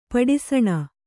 ♪ paḍi saṇa